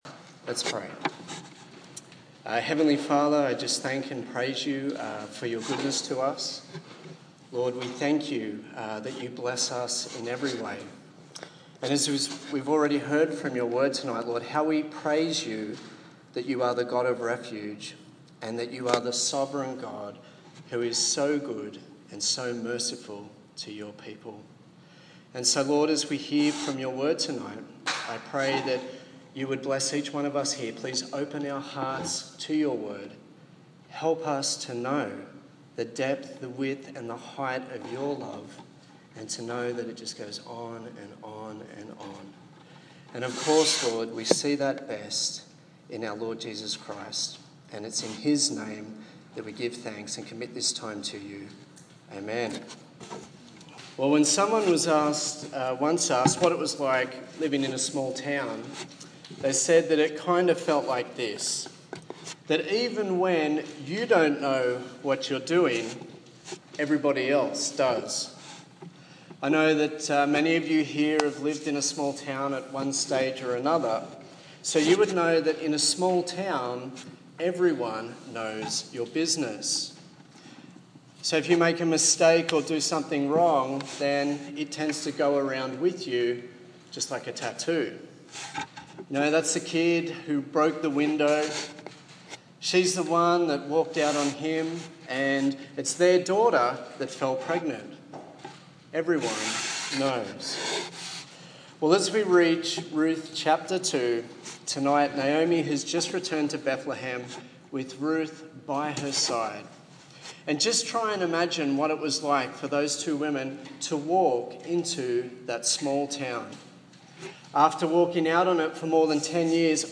The second sermon in the series on Ruth